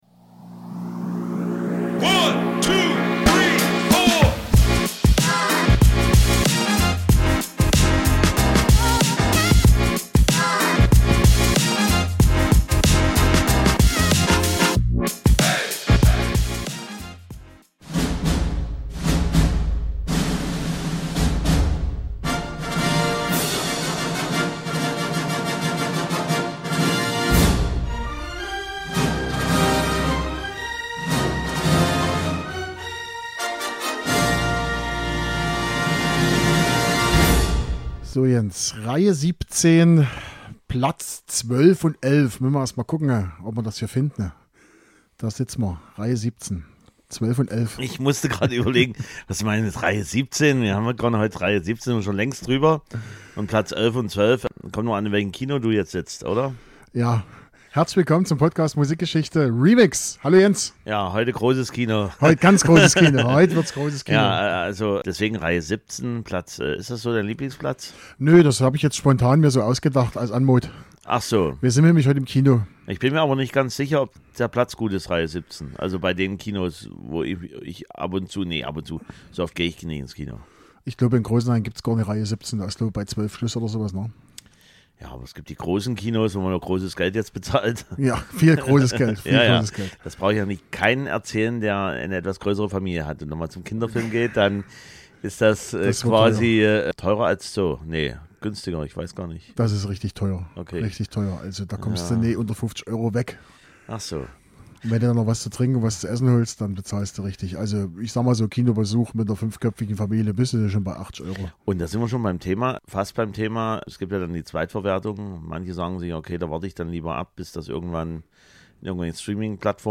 Vorab ... wir reden und essen diesmal sehr viel.